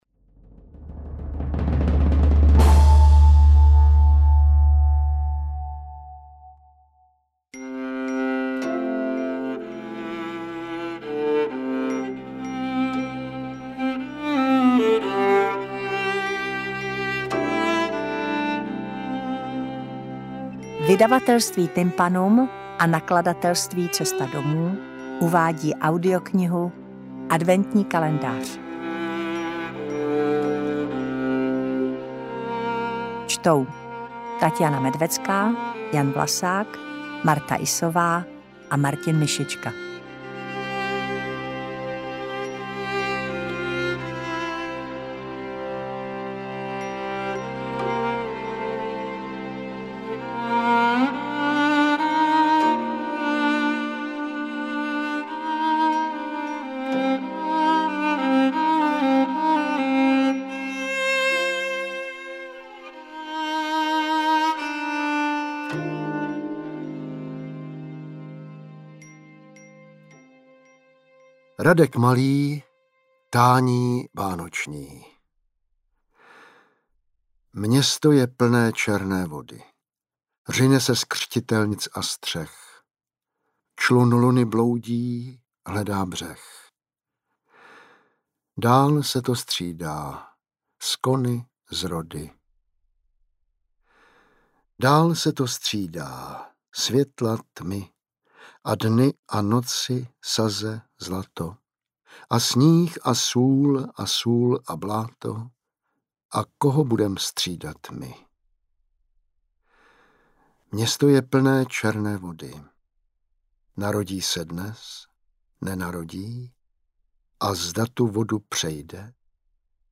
Interpreti:  Martha Issová, Taťjána Medvecká, Martin Myšička, Jan Vlasák
Dvacet čtyři adventních příběhů pro čtyři hlasy.
AudioKniha ke stažení, 24 x mp3, délka 1 hod. 46 min., velikost 96,8 MB, česky